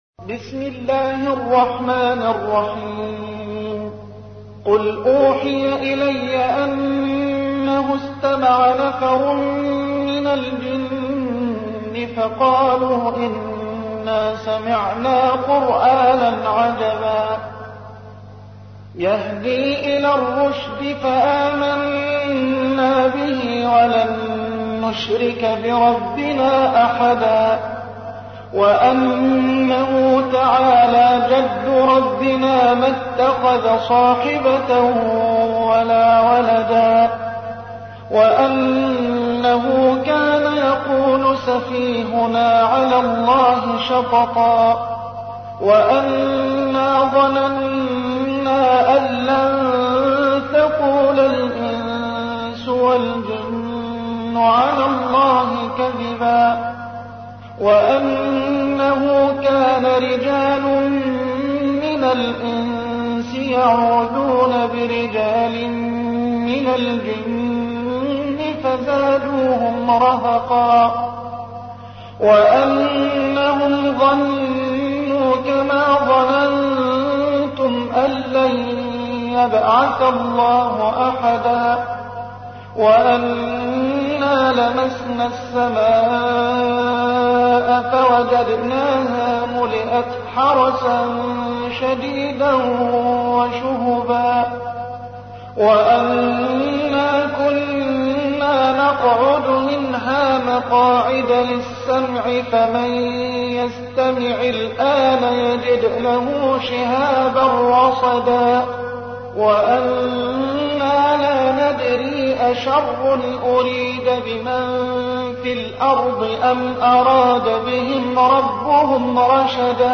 تحميل : 72. سورة الجن / القارئ محمد حسان / القرآن الكريم / موقع يا حسين